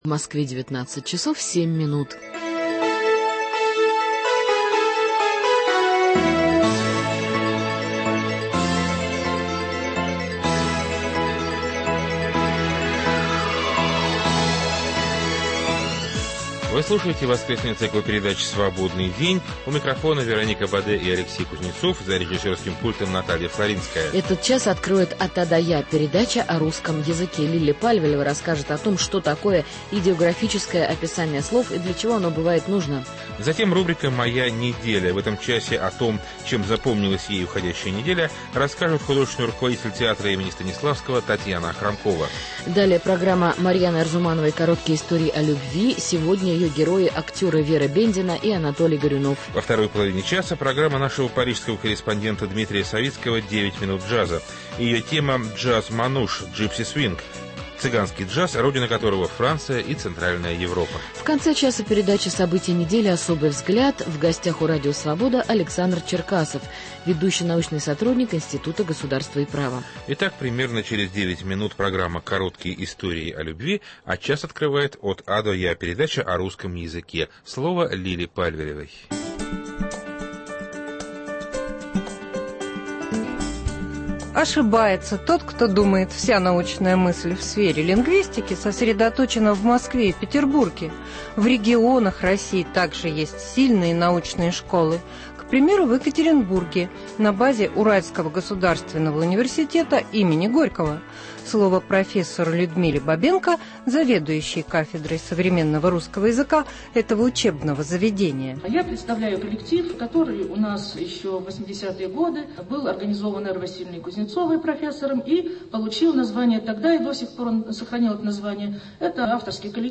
Во второй половине часе – программа «9 минут джаза». Ее тема - Джаз-Мануш, Gipsy Swing, цыганский джаз, родина которого - Франция и центральная Европа.